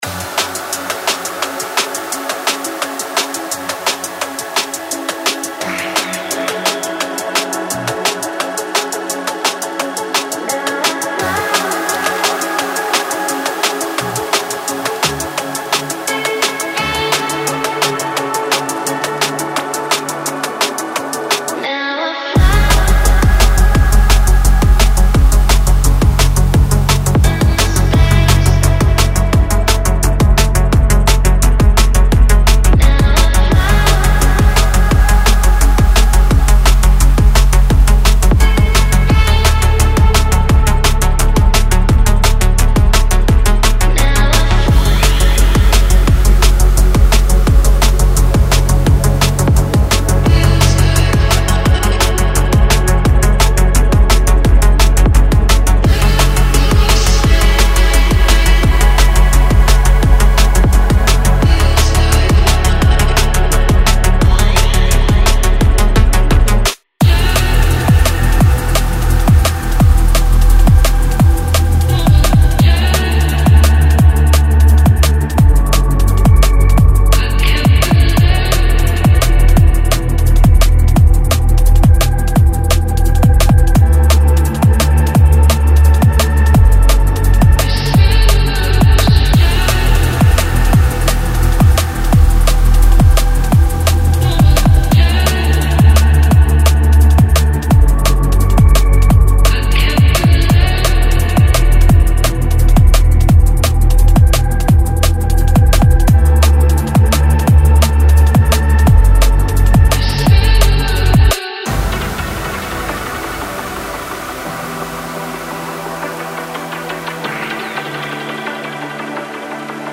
哀愁を帯びたピアノの進行が催眠的なブレイクと輝くボーカルと交差し、夕暮れの都市風景を霧のように漂っていきます。
このパックはムードに満ちており、人間的な温もりを感じるメロディーは、記憶と感情の輝きとともにあなたの心に響きます。
ベースラインは影の中でとぐろを巻き、深く、豊かで、落ち着きがありません。
夢のようなシンセはリバーブに浸された回廊を縫うように漂います。
ドラムはまさに生きているような感触で、反応性がありグルーヴに満ちています。
パーカッシブなFXや雰囲気を彩るアクセントが空間と色を加え、ミックスの隙間を自然に満たします。
繊細でソウルフル、そしてどこか幽玄。
デモサウンドはコチラ↓
Genre:Liquid